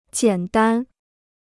简单 (jiǎn dān) 무료 중국어 사전